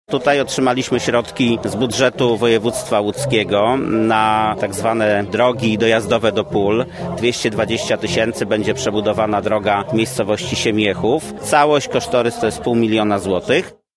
– mówił Michał Włodarczyk, wójt gminy Widawa.